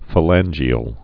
(fə-lănjē-əl, fā-) also pha·lan·gal (fə-lănggəl, fā-) or pha·lan·ge·an (fə-lănjē-ən, fā-)